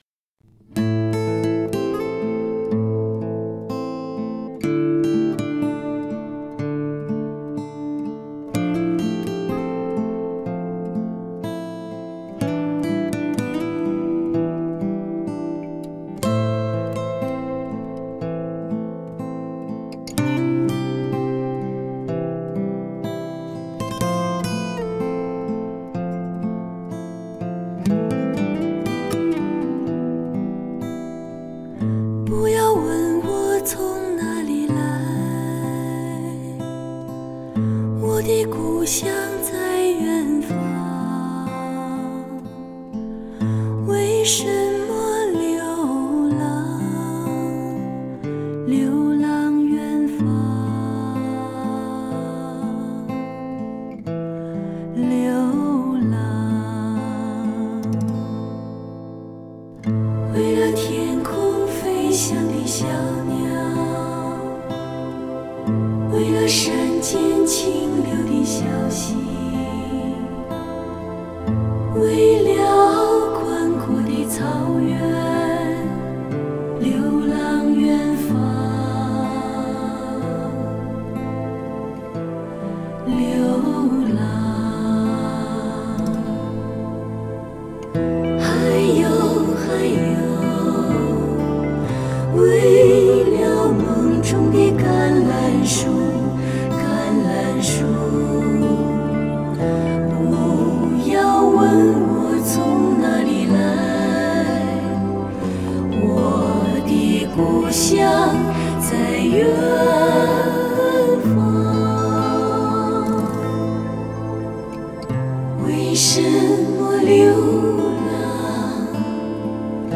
类别: 摇滚